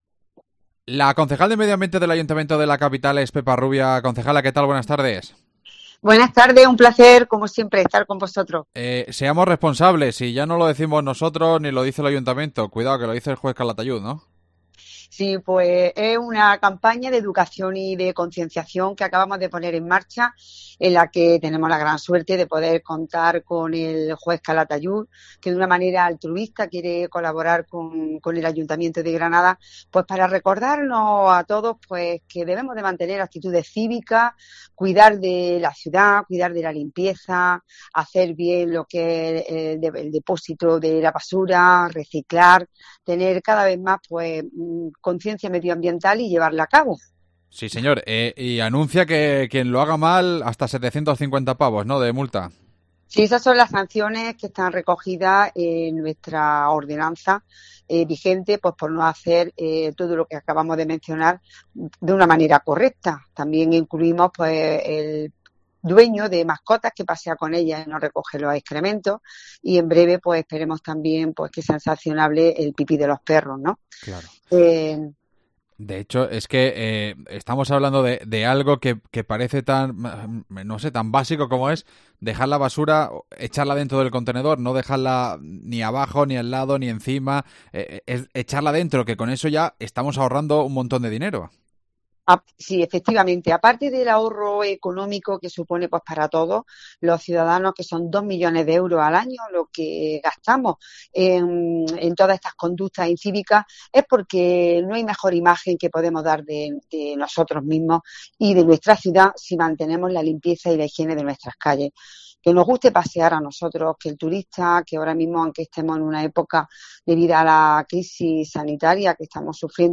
AUDIO: La concejala de medio ambiente, Pepa Rubia, nos cuenta todos los detalles